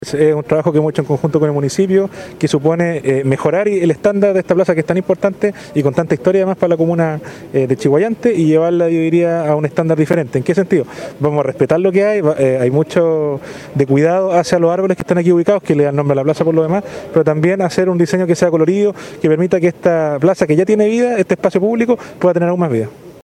“Es una intervención de 192 millones de pesos y que va a consistir, básicamente, en un mobiliario distinto, para que los vecinos se encuentren”, explicó el seremi de Vivienda y Urbanismo, James Argo.